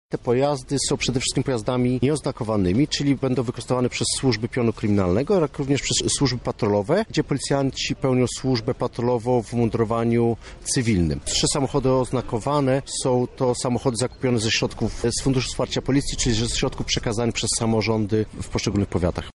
O szczegółach mówi nadinspektor Dariusz Działo Lubelski Komendant Wojewódzki Policji.